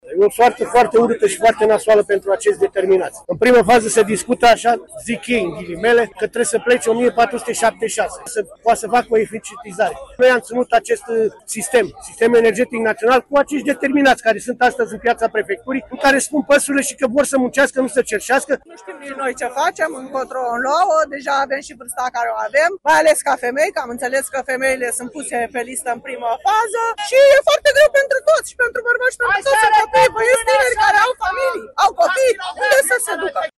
Peste 200 de salariați din cadrul Complexului Energetic Oltenia protestează, la această oră, în fața companiei, la Târgu Jiu.
Nu știm nici noi ce facem, încotro o luăm”, mărturisește o angajată
04mar-12-CORESP-GJ-vox-protest-CE-Oltenia-.mp3